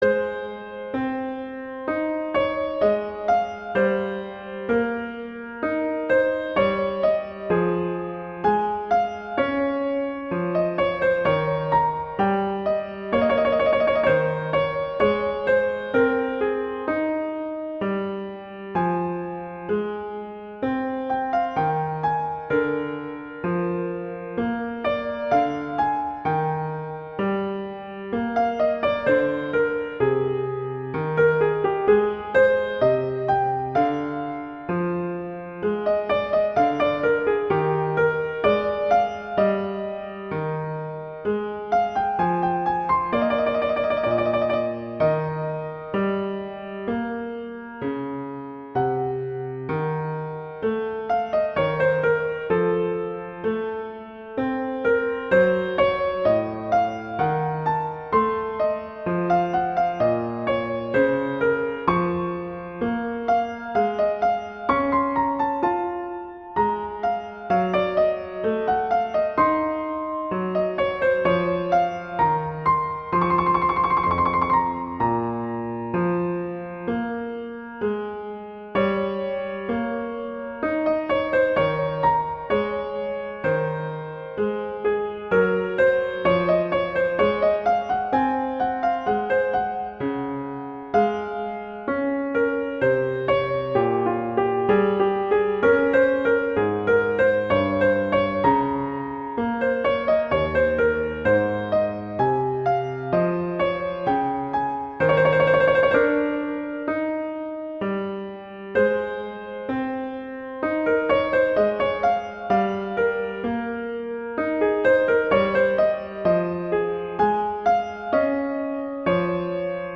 classical, concert
♩=32 BPM (real metronome 40 BPM)